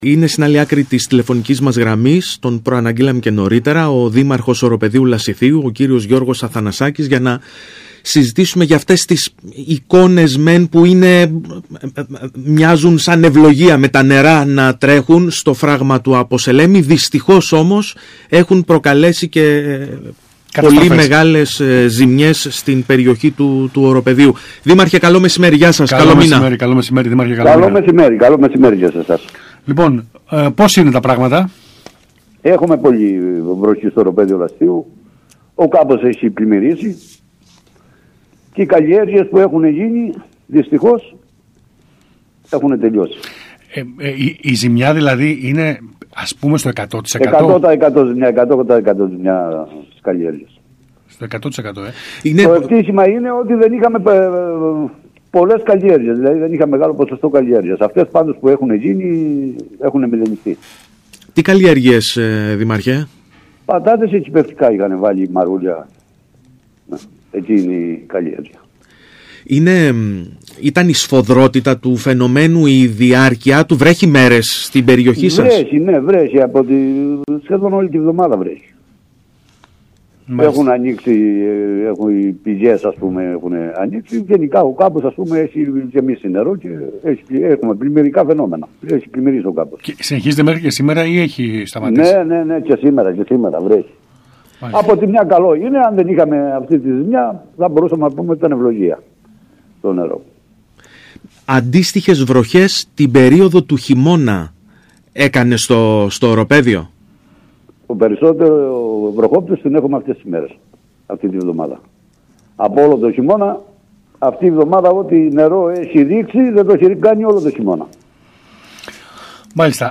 Ακούστε τον Δήμαρχο Οροπεδίου Λασιθίου κ. Αθνασάκη: